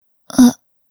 语气词-惊讶.wav